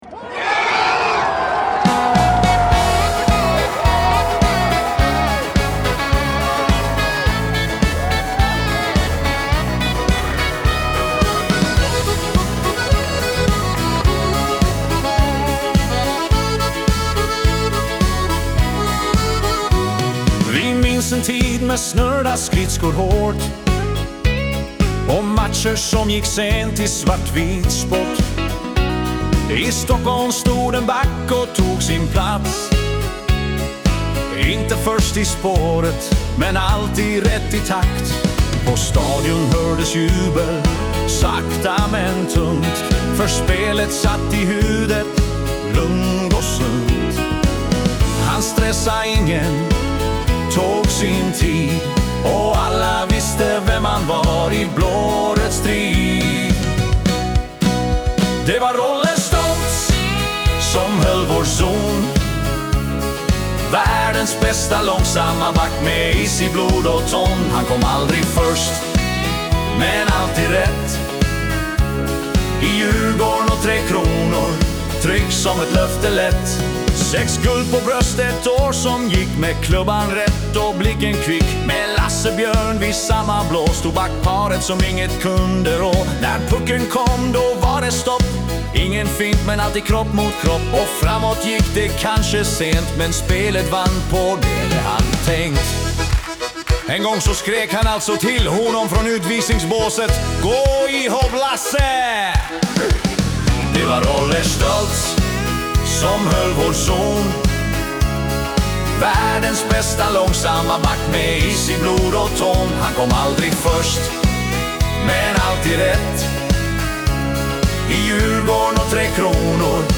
ett svenskt dansband